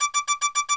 HeatShieldsAlarm.wav